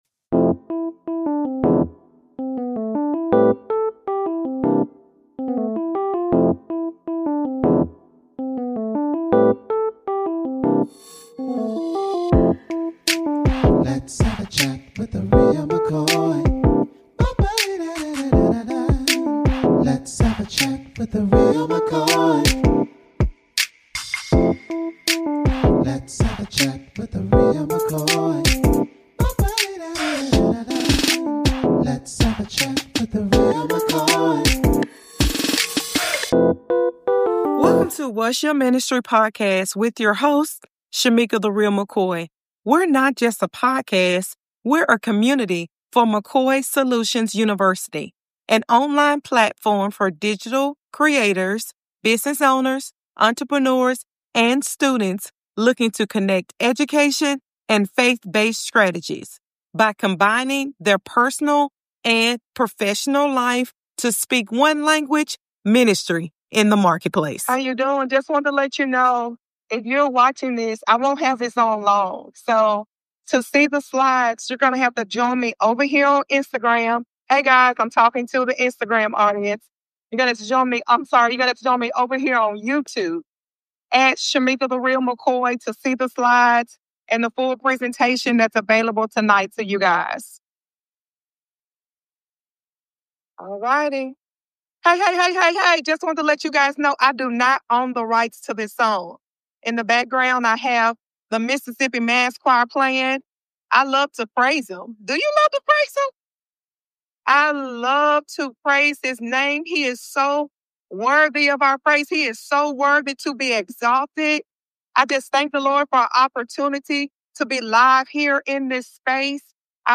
Building Business On A Solid Foundation_LIVE Series